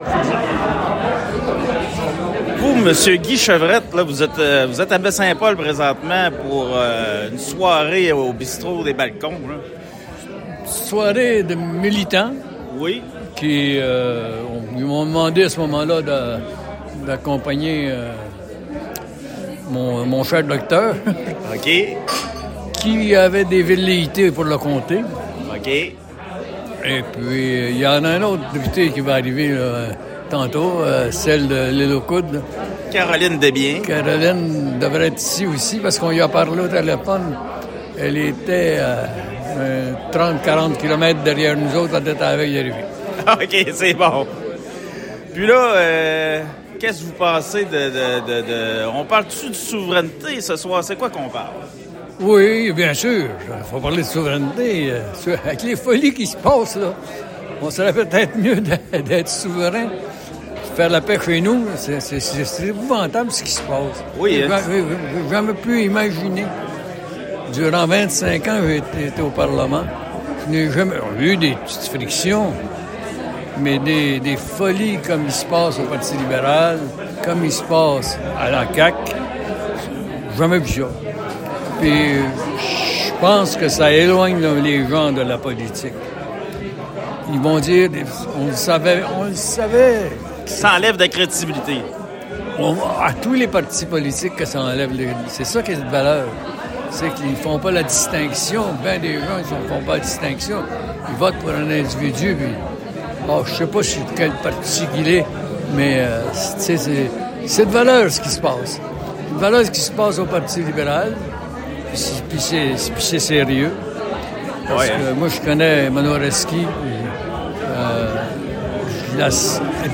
À la suite de l’événement, M. Chevrette a accordé une entrevue à Média Photos Charlevoix. Ce segment de 8 minutes est un vrai bijou car M. Chevrette ne mâche pas ses mots et explique sa pensée envers la situation politique provinciale, la souveraineté et bien plus.